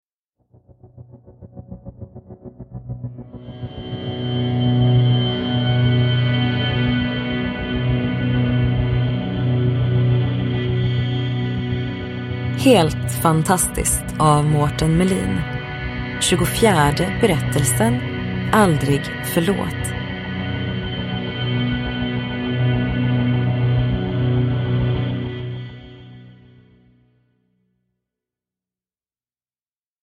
Aldrig förlåt : en novell ur samlingen Helt fantastiskt – Ljudbok – Laddas ner